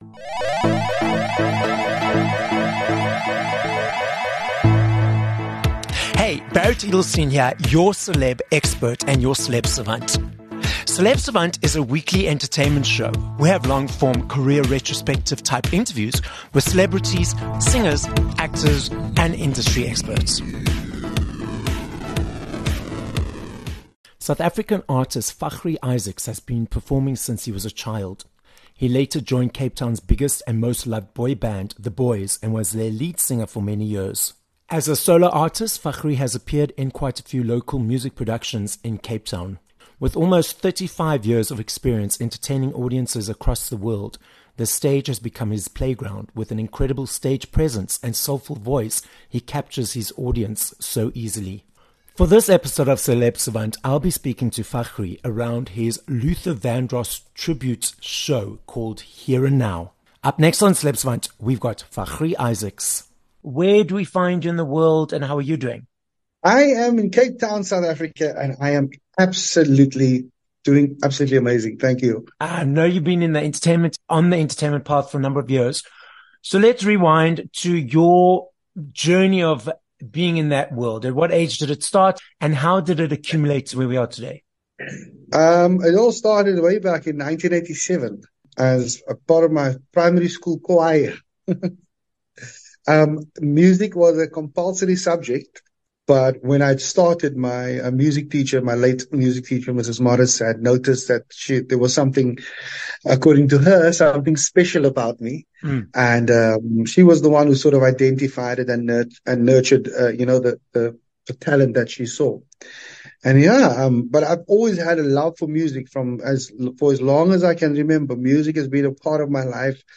24 Dec Interview